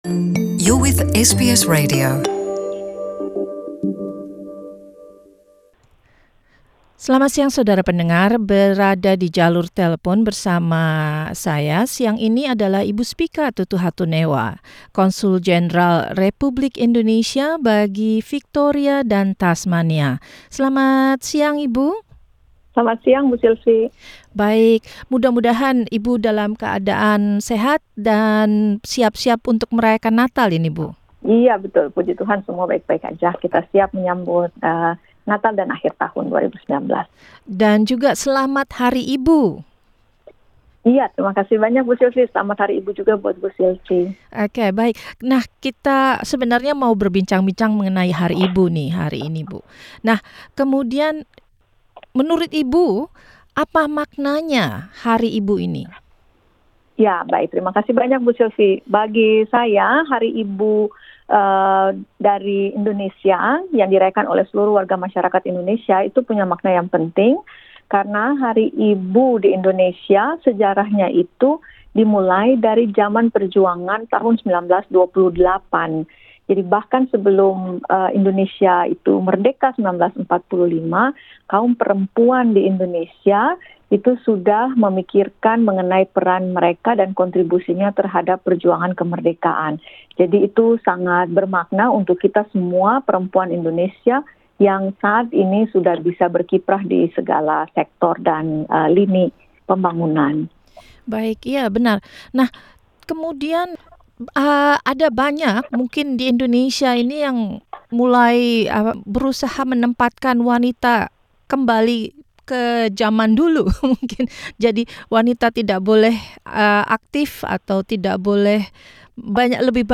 Ibu Spica Tutuhatunewa, Konsul Jendral Republik Indonesia bagi Victoria dan Tasmania menjelaskan apa makna Hari Ibu dan pencapaian para perempuan baik di Indonesia maupun di Australia.